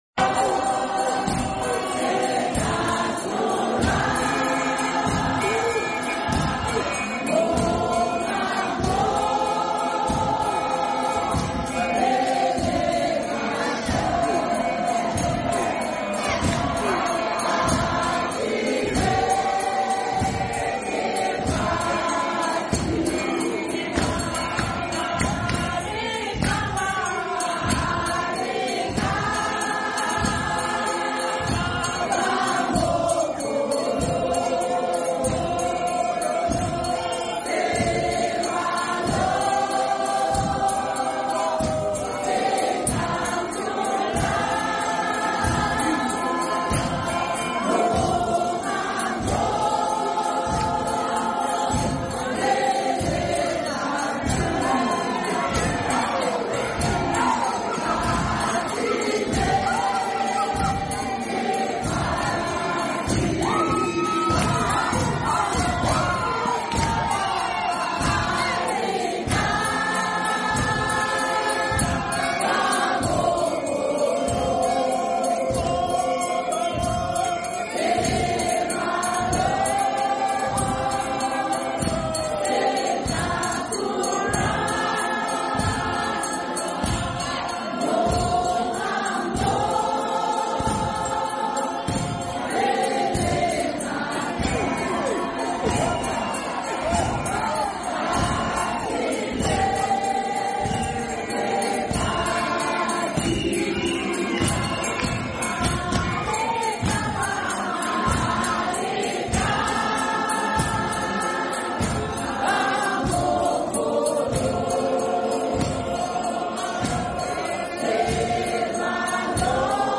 Churches united in worship 🙌🏾 singing hymn 151